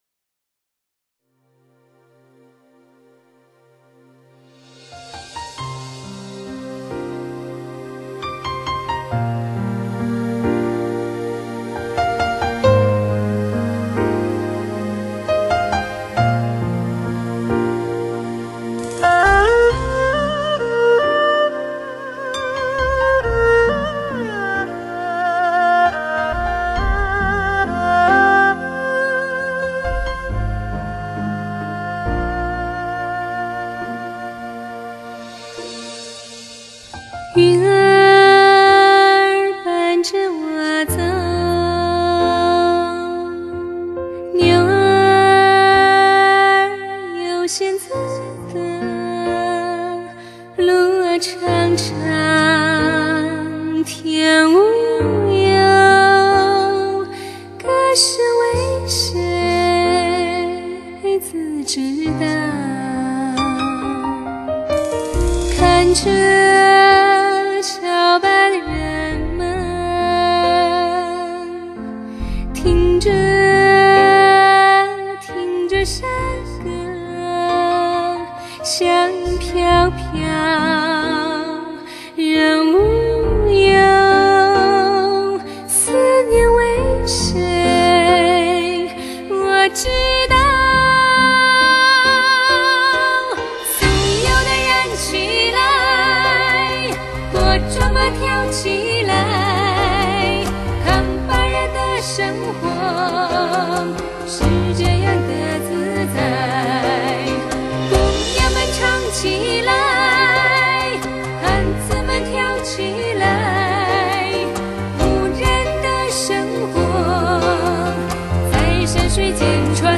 悠扬跌宕的笛韵，暖人心脾的弦音，都借由DTS完美环绕音效技术，而进发前所未有的听觉享受，天籟留音。